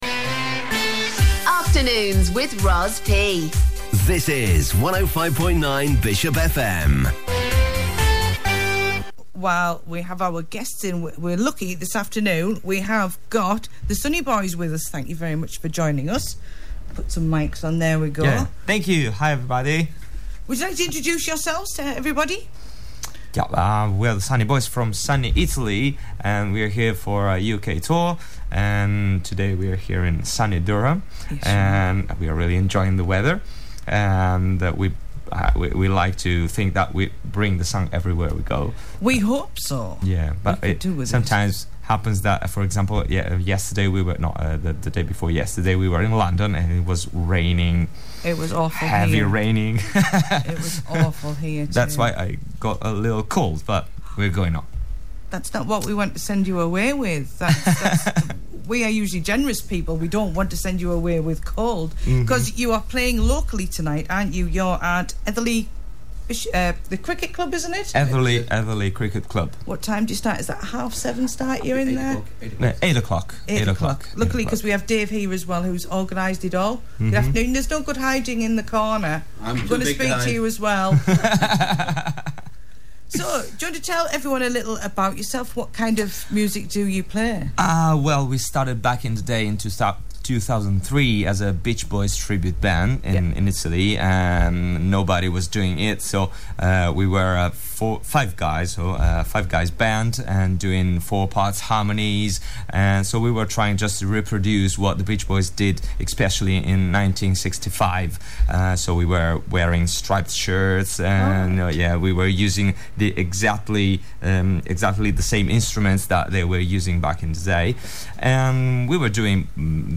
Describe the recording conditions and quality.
The Sunny Boys from Italy during their U.K. Tour take time out to visit 105.9 Bishopfm in Bishop Auckland ,Durham for an interview